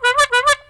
Add bicycle sounds (CC0)
sounds_bicycle_horn.ogg